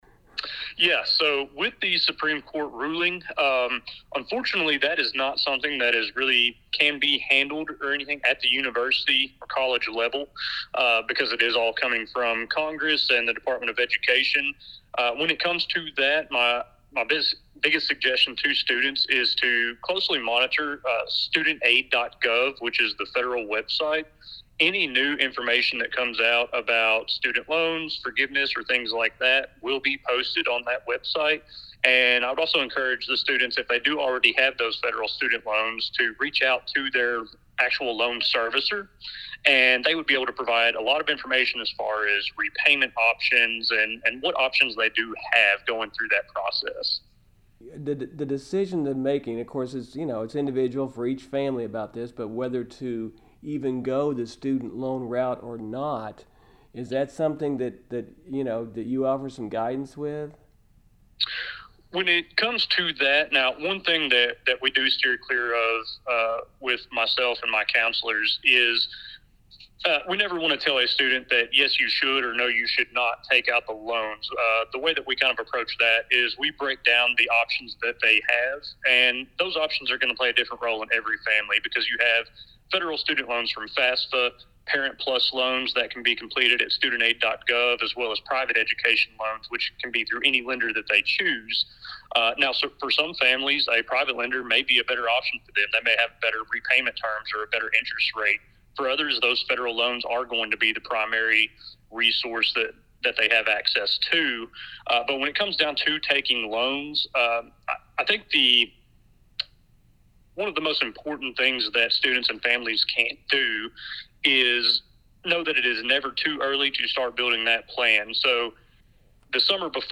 Here's the interview